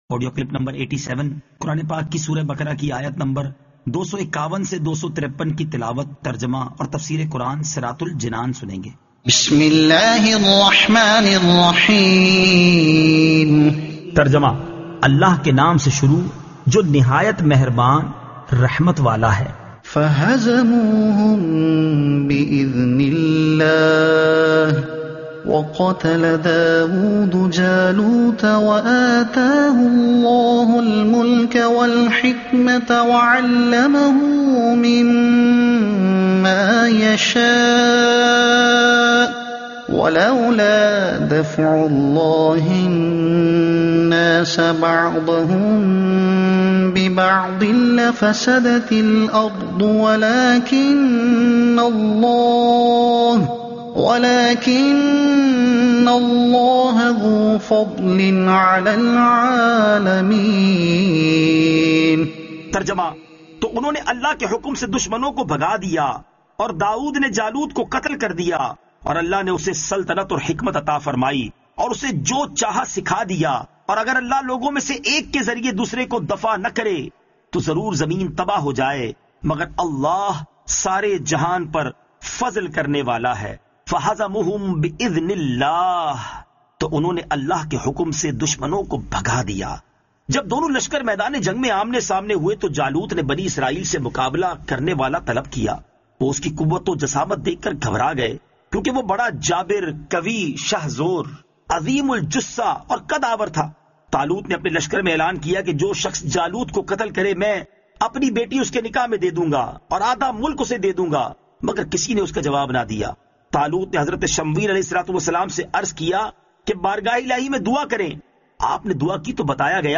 Surah Al-Baqara Ayat 251 To 253 Tilawat , Tarjuma , Tafseer